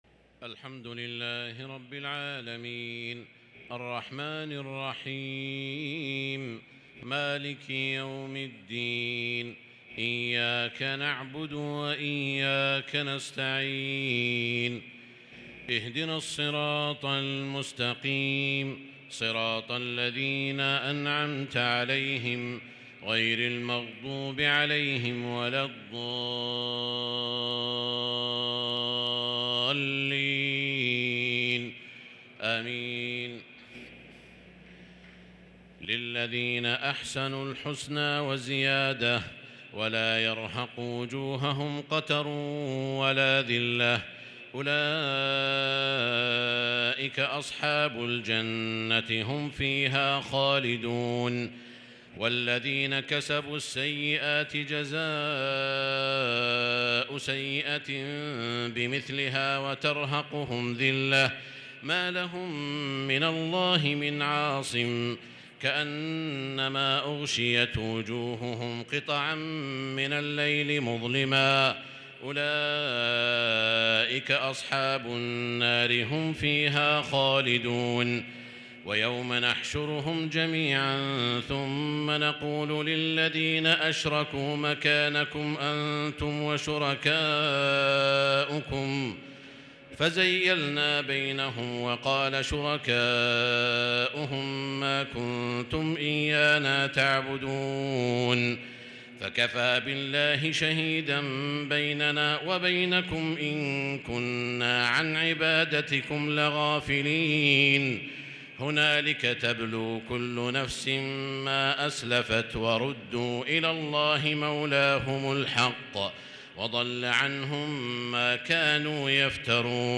صلاة التراويح ليلة 15 رمضان 1443 للقارئ سعود الشريم - الثلاث التسليمات الأولى صلاة التراويح